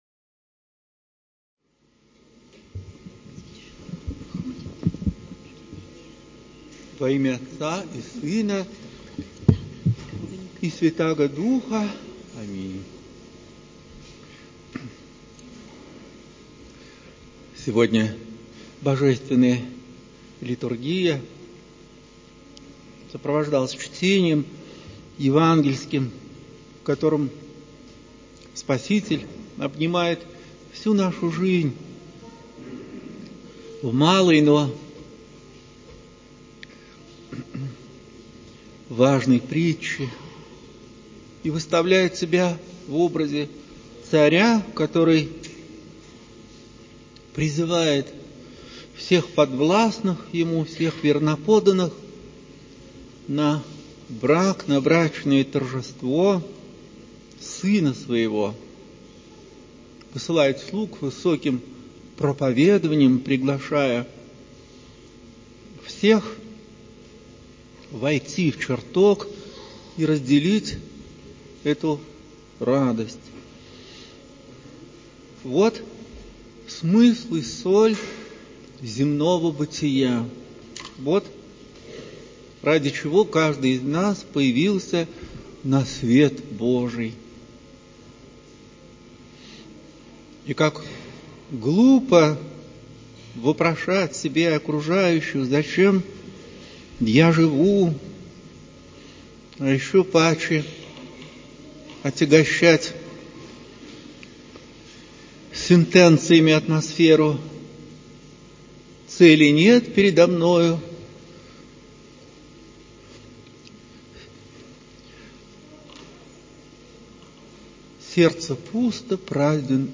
Проповедь протоиерея Артемия Владимирова на Евангелие от Матфея, гл. XXII, ст.1-14. В храме Алексея человека Божия ставропигиального женского Алексеевского монастыря. На литургии, 18 сентября 2022.